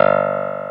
CLAVI6 G1.wav